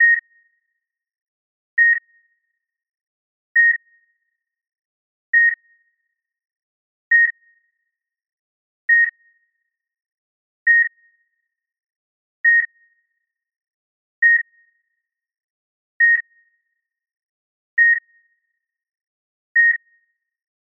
Звуки кардиографа
Плохое сердцебиение звук